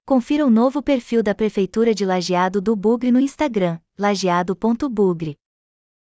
Text-to-Speech.wav